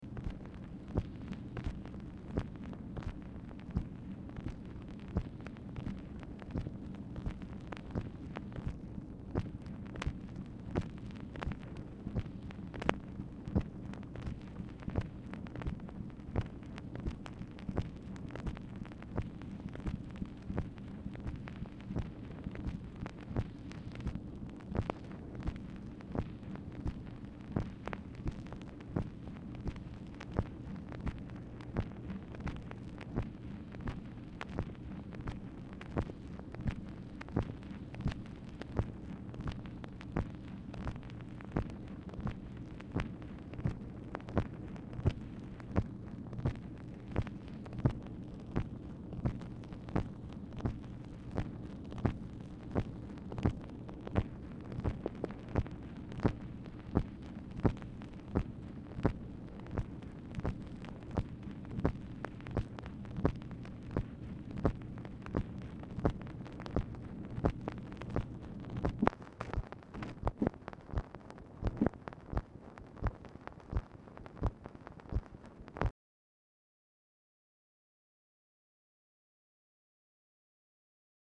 Telephone conversation
MACHINE NOISE
Format Dictation belt
Location Of Speaker 1 Oval Office or unknown location